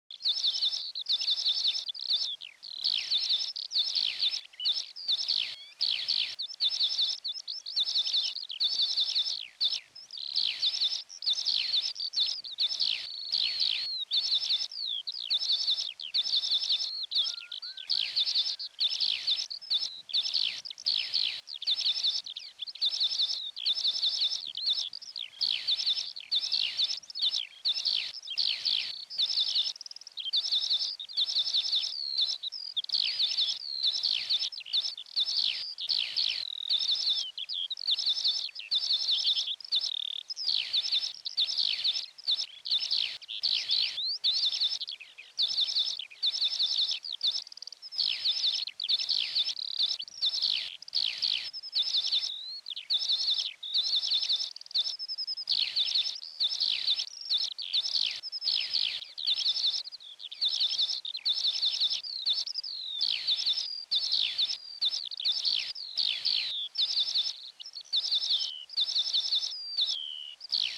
Text and sound installation using brass lettering, paperclips and Morse code Lark song
This audio comprises the words "She Dreams" repeated in Morse code, created from lark song recordings.